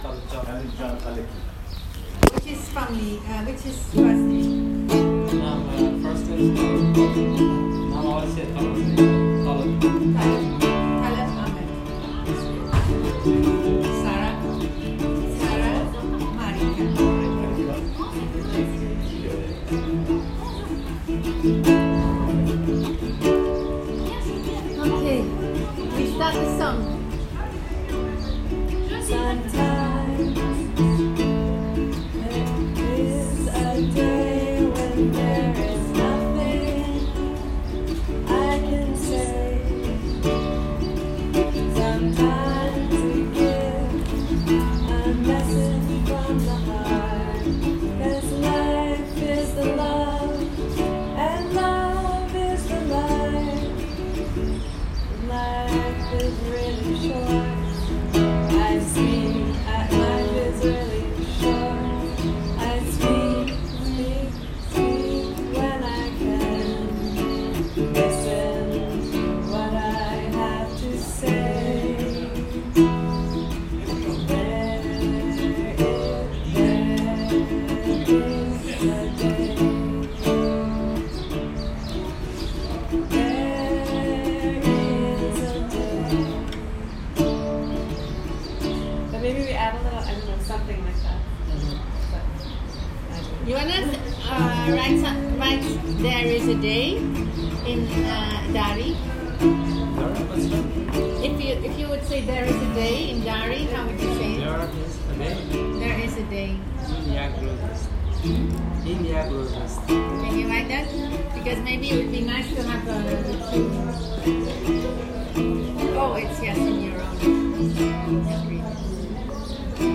This is a recording of the song from the day we wrote it at the center: